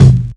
Waka KICK Edited (37).wav